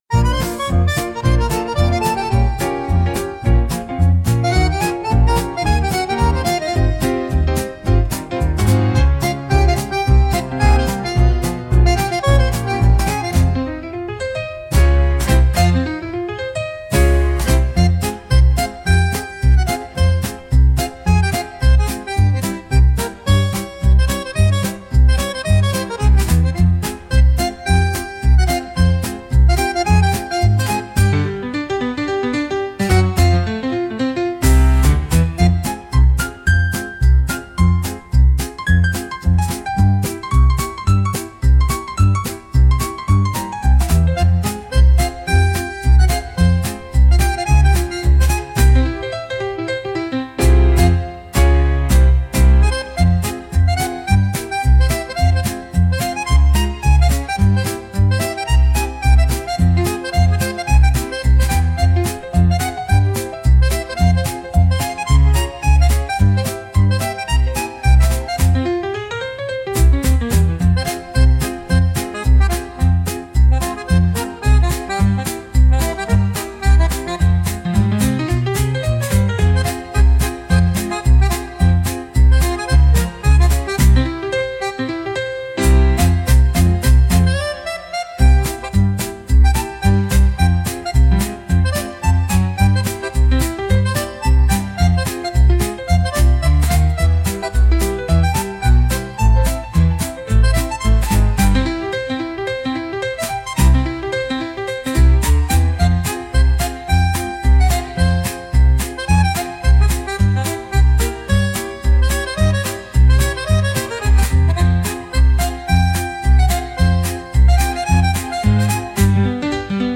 Instrumental / 歌なし
ピアノが奏でる、コロコロと転がるような可愛らしいタンゴ・ナンバー。
タンゴのリズムでありながら重苦しさはなく、キッズやジュニア選手にも親しみやすい「おしゃれ」な雰囲気です。
リズムが明確で非常に踊りやすく、ニコニコと笑顔で踊れるような一曲。